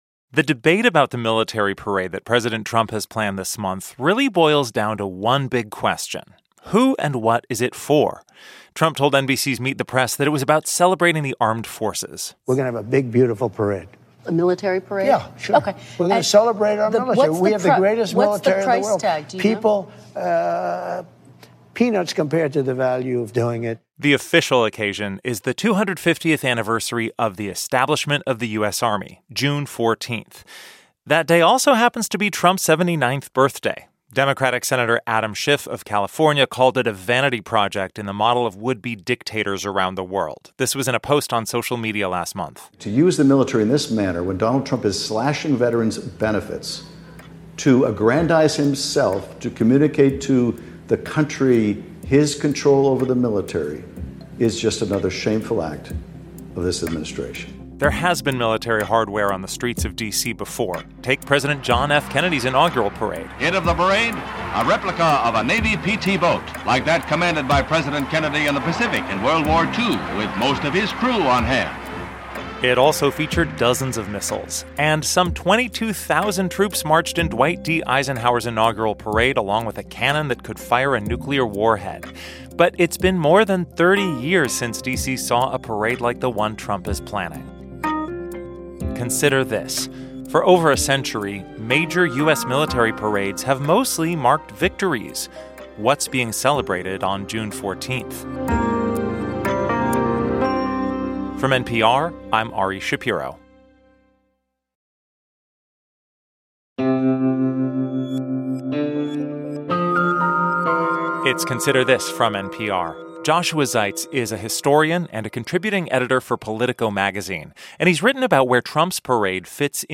NPR's Ari Shapiro speaks with historian